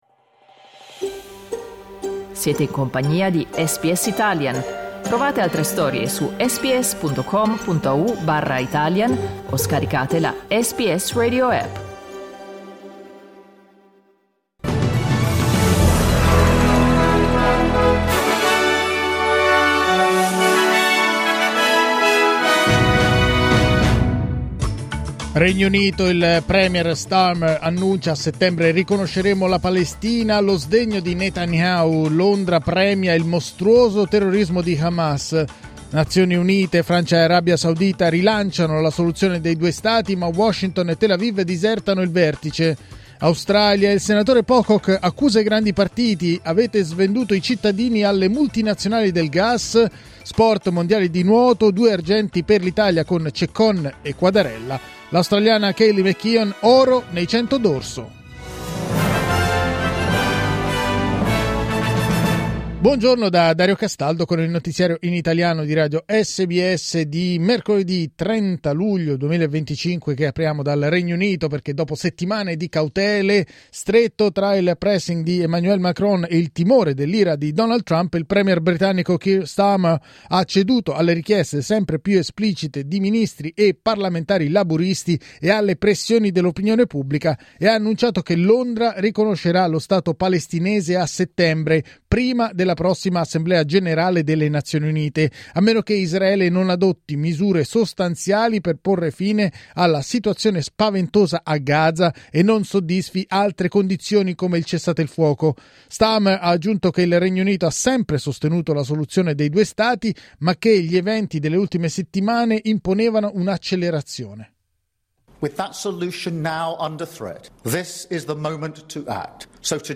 Giornale radio mercoledì 30 luglio 2025
Il notiziario di SBS in italiano.